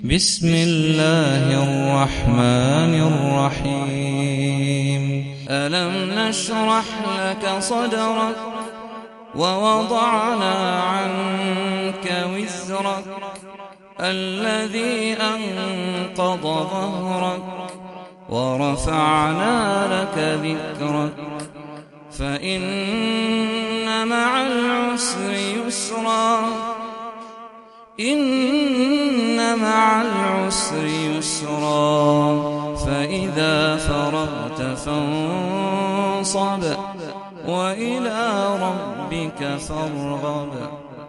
سورة الشرح - صلاة التراويح 1446 هـ (برواية حفص عن عاصم